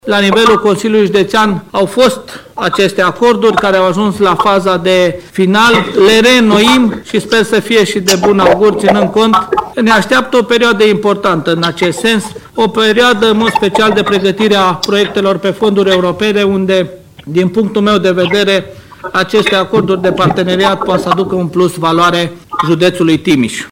Preşedintele Consiliului Judeţean Timiş, Călin Dobra.